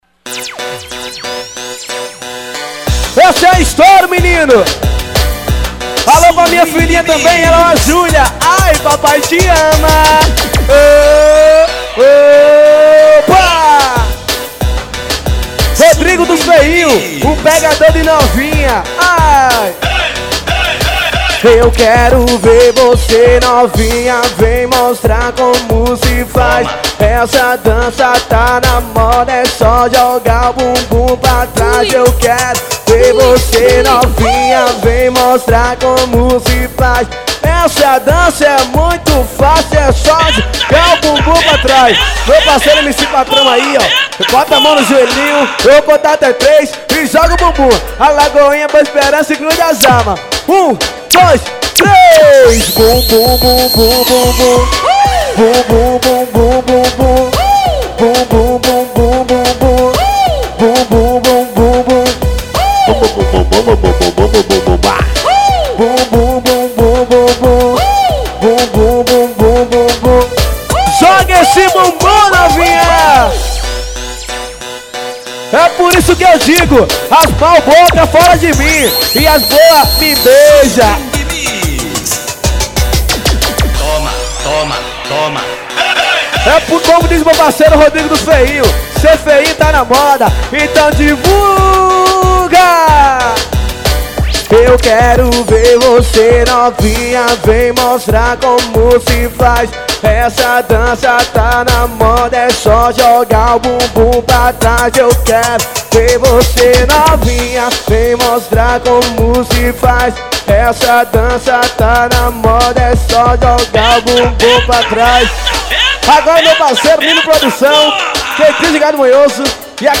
AO VIVO..